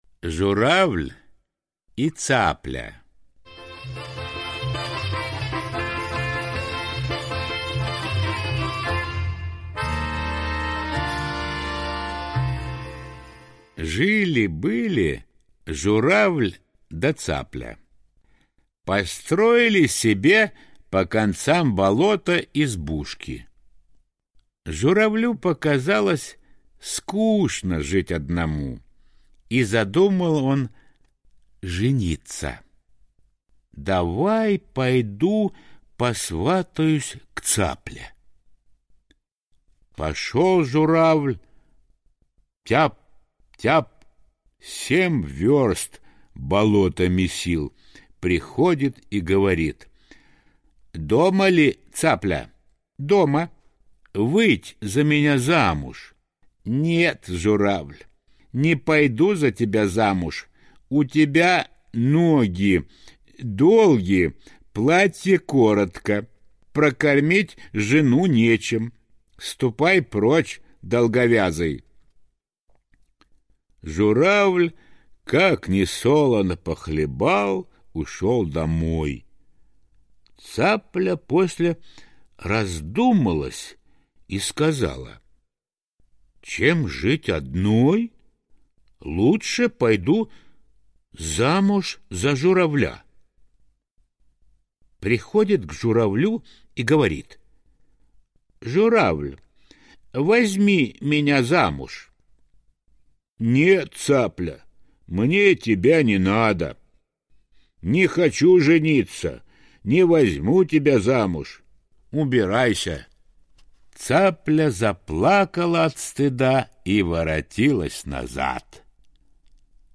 Журавль и цапля Аудиосказка
теги: аудиосказка, сказка, русская народная сказка